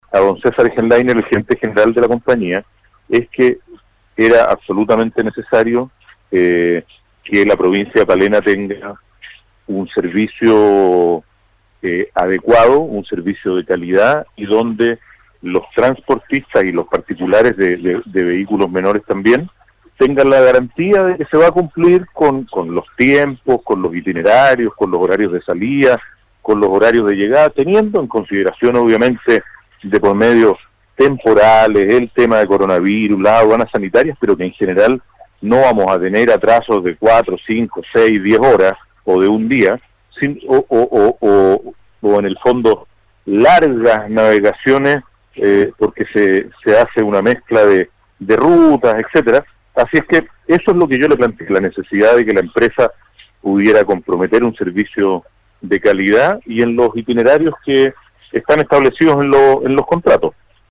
Las demandas por los mejores servicios para con el servicio, si bien la han hecho presentes los transportistas, representan a toda la comunidad de Palena, indicó el gobernador provincial.